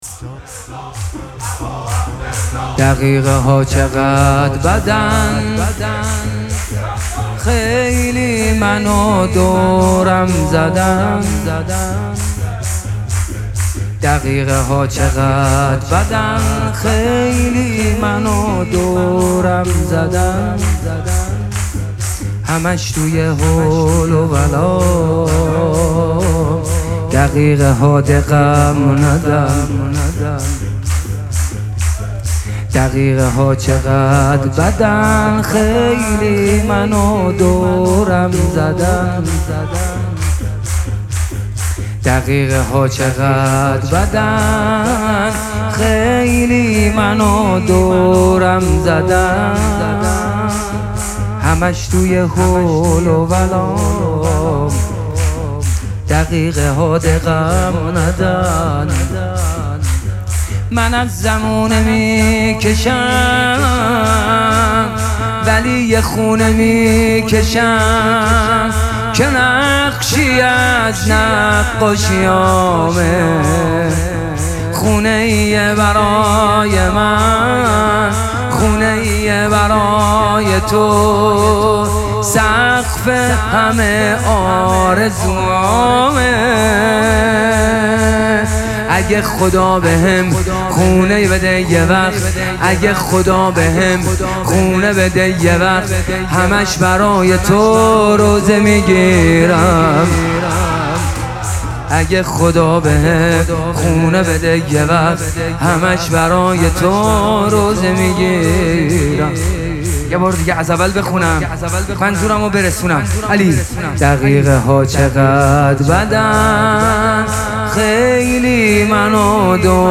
مراسم مناجات شب بیستم ماه مبارک رمضان
شور
مداح